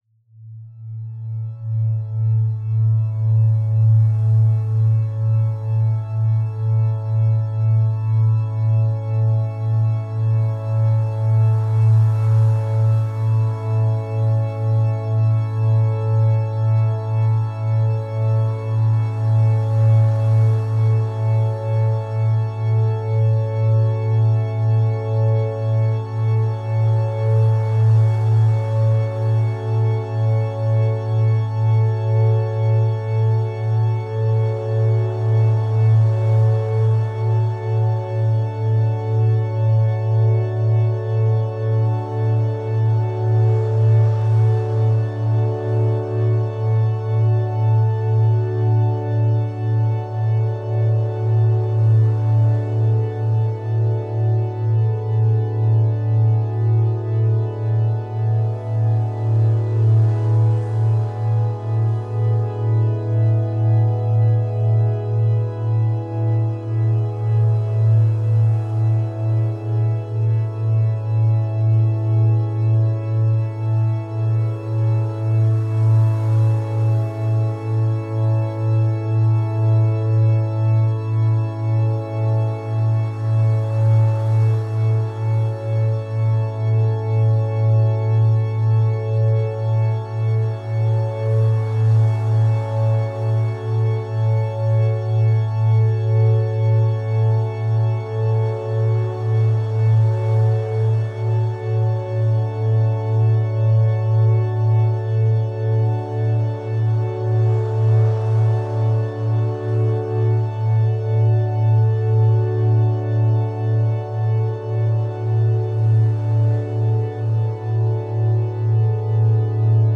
90hz - Gamma Binaural Beats for Concentration